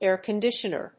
/ˈɛrkʌˌndɪʃʌnɝ(米国英語), ˈerkʌˌndɪʃʌnɜ:(英国英語)/
フリガナエーカンディシャナー
アクセントáir condítioner